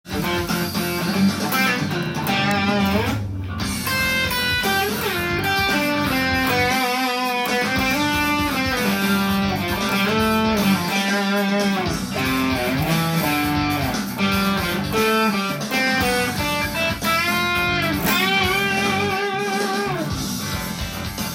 ミクソリディアンスケール
明るい雰囲気でメロディーが作りやすいので重宝されます。
アドリブで弾いてみました